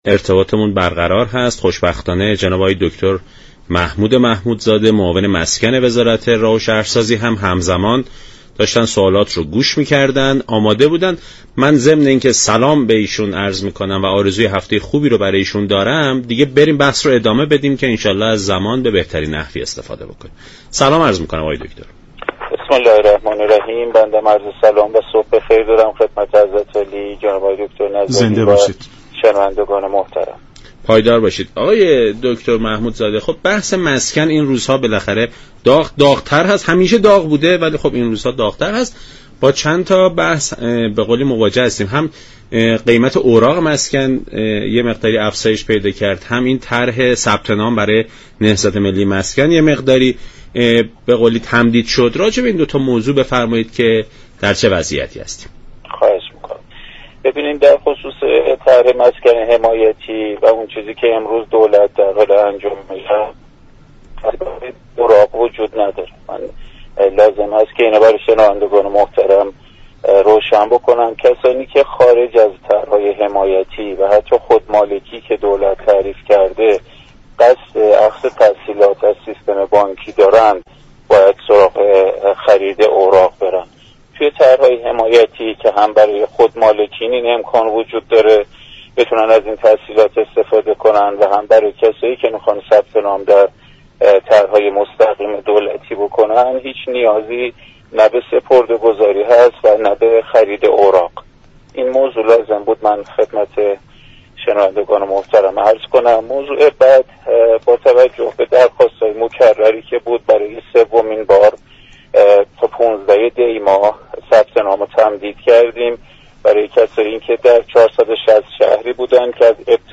به گزارش شبكه رادیویی ایران، محمد محمودزاده معاون مسكن وزارت راه و شهرسازی در برنامه «سلام صبح بخیر» رادیو ایران به جزئیات نهضت ملی مسكن و خرید اوراق مشاركت پرداخت و گفت: كسانی كه خارج از طرح های حمایتی دولت، قصد اخذ تسهیلات از سیستم های بانكی دارند باید سراغ خرید اوراق روند.